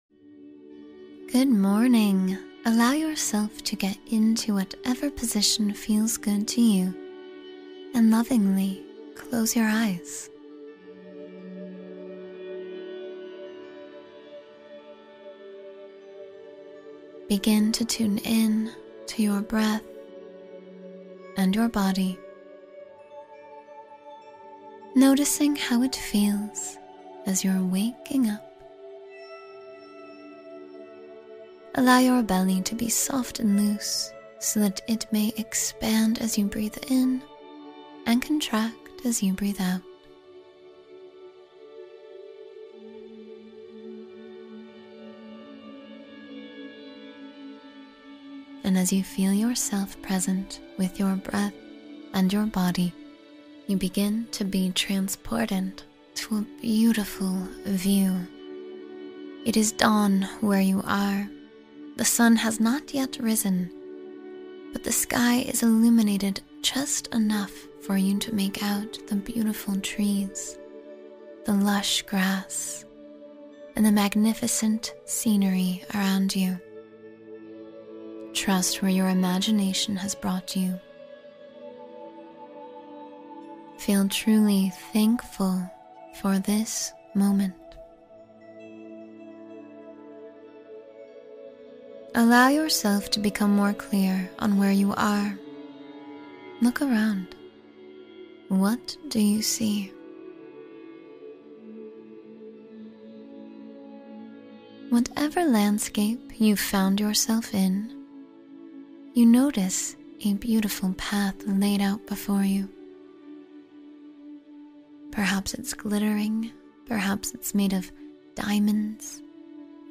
Visualize Positivity in Nature’s Peace — Meditation for Upliftment